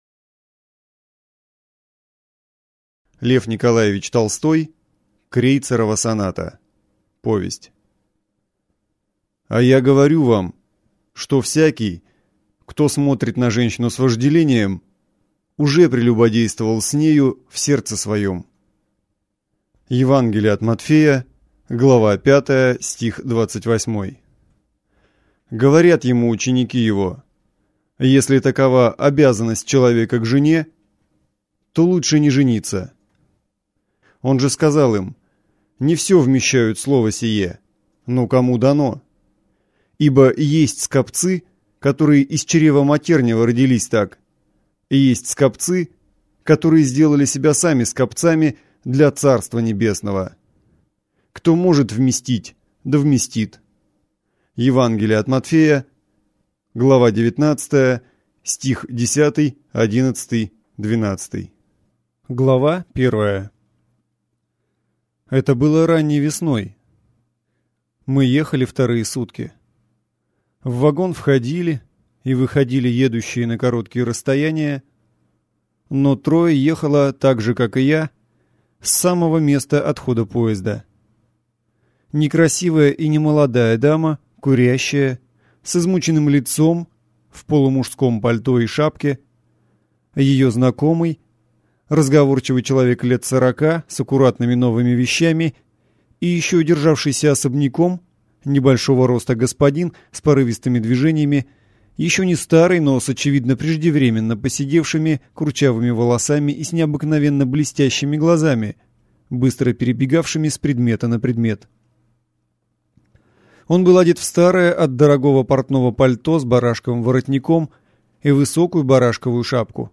Аудиокнига Крейцерова соната - купить, скачать и слушать онлайн | КнигоПоиск